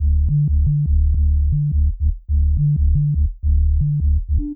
000-sine-bass.wav